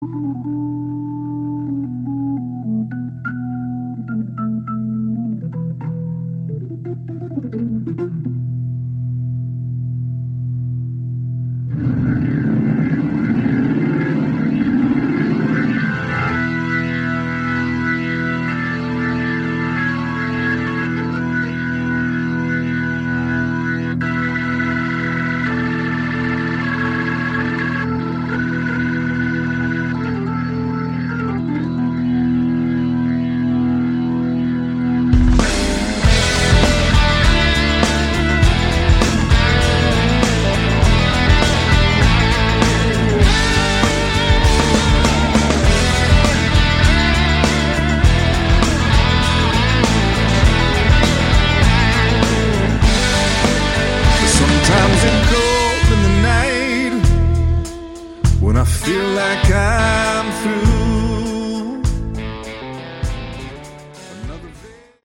Category: Southern Rock
vocals
guitars
drums
bass
hammond, piano